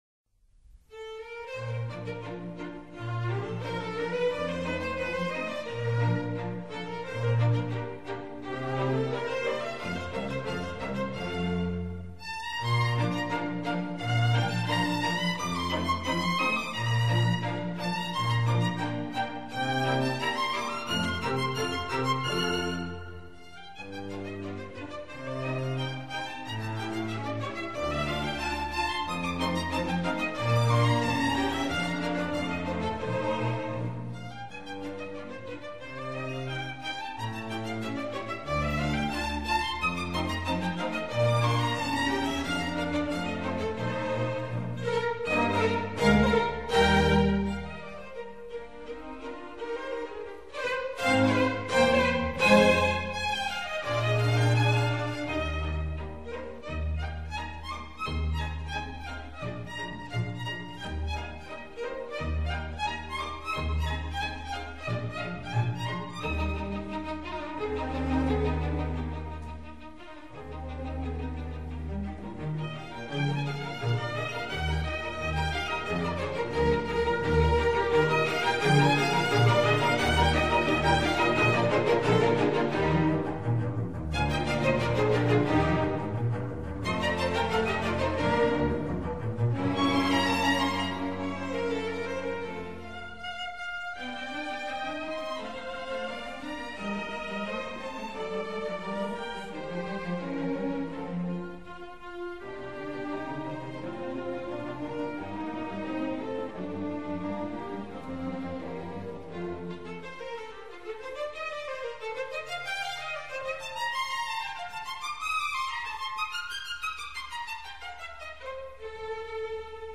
内  容： 世界古典名曲 Highlights